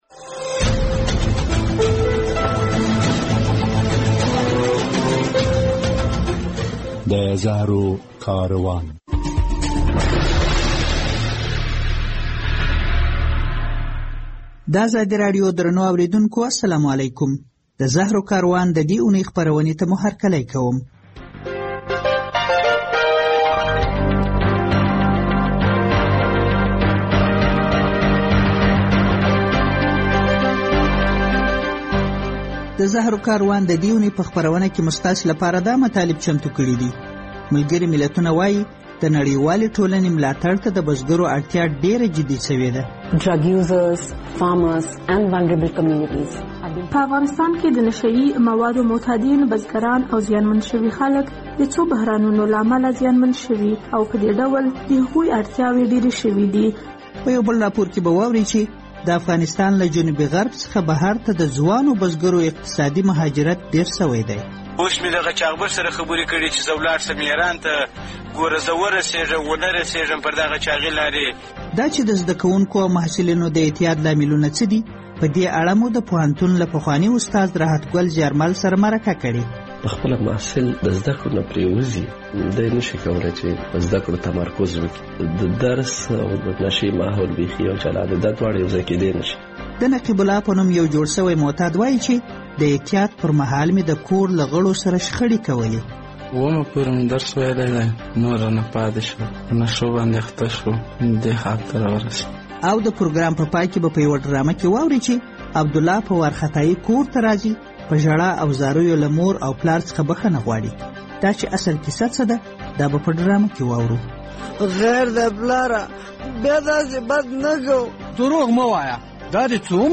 د زهرو کاروان په دې خپرونه کې اورئ چې ملګري ملتونه وایي، مرستو ته د بزګرو اړتیا د هر بل وخت په پرتله ډېره شوې ده. د افغانستان له جنوب څخه په یوه راپور کې اورئ چې بهر ته د ځوانو بزګرو اقتصادي کډوالي ډېره شوې ده.